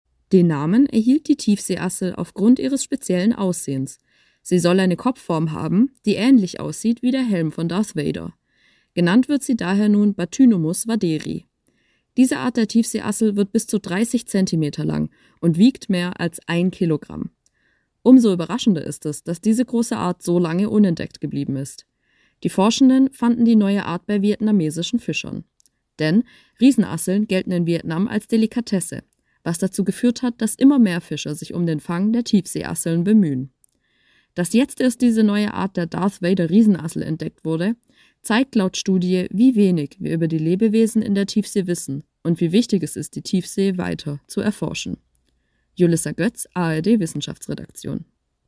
Anmoderation: